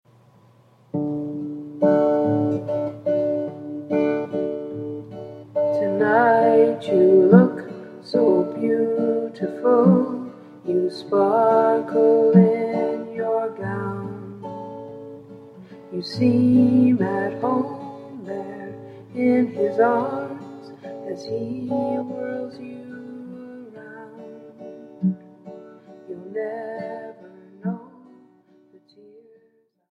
Sample from the Vocal MP3